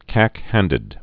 (kăkhăndĭd)